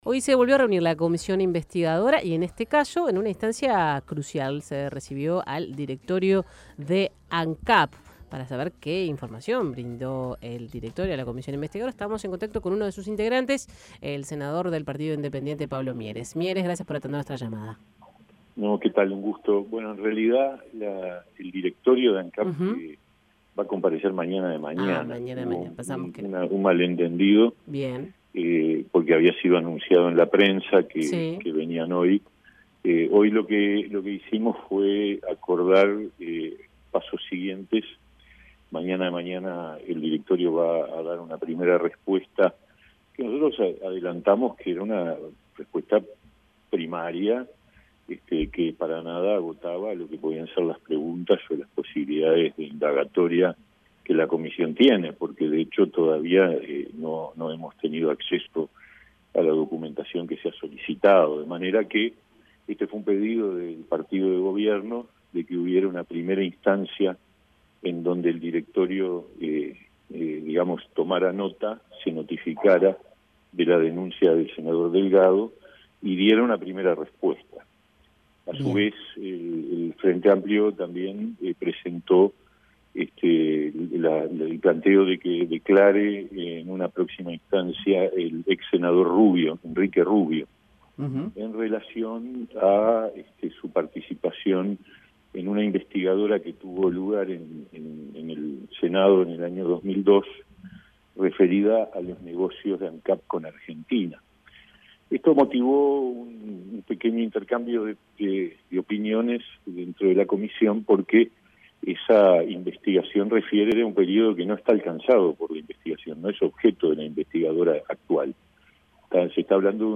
Escuche al senador Pablo Mieres